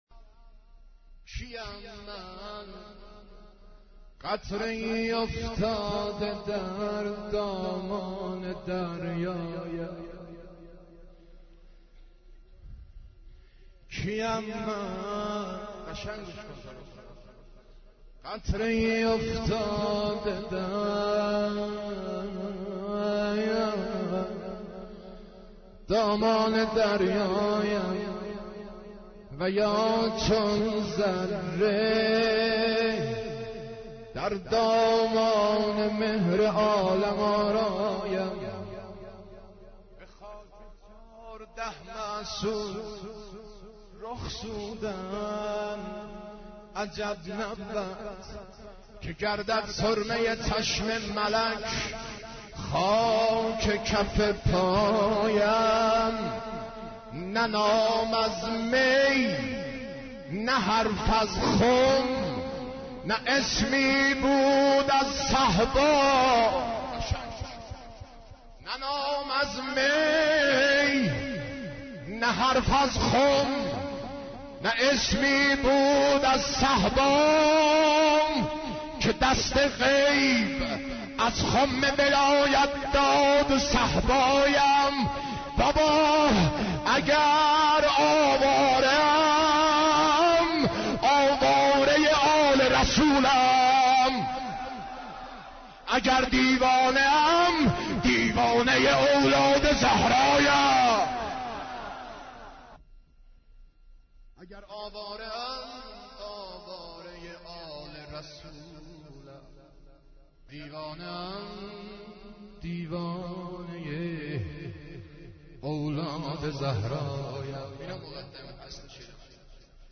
مداحی امام حسین ع 14